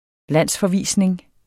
Udtale [ ˈlanˀsfʌˌviˀsneŋ ]